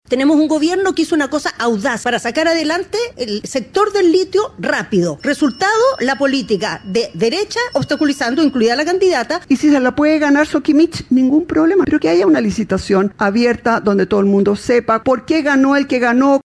Debate Icare por permisología: Carolina Tohá y Evelyn Matthei